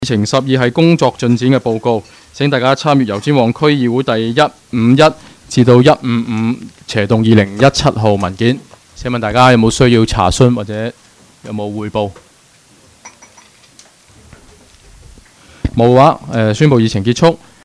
区议会大会的录音记录
油尖旺区议会会议室